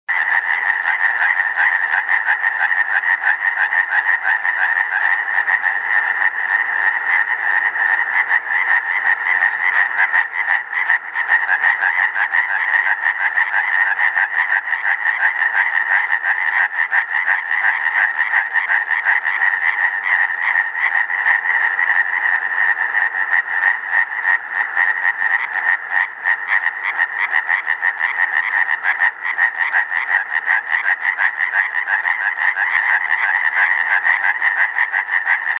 Sonido de la Ranita de San Antonio.mp3